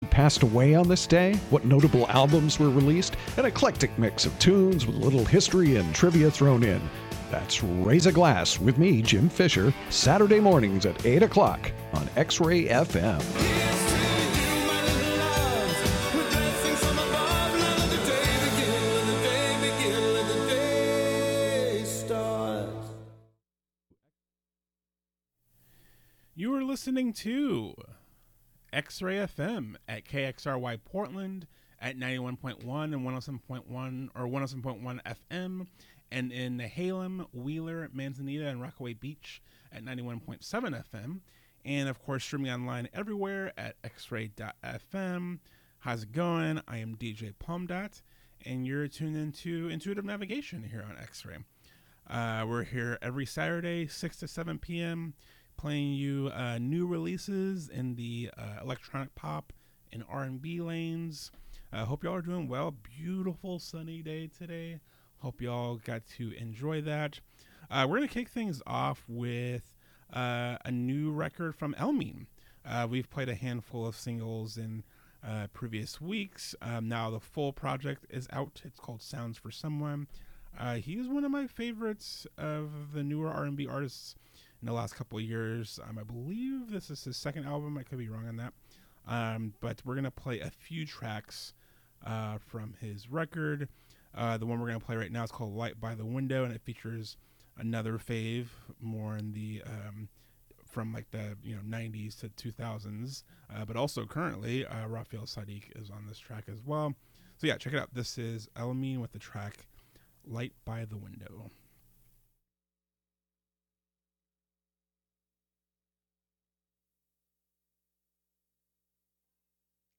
The latest in electronic pop, R&B, and other soulful music.
Feels-talk, moon-talk, and sultry jams.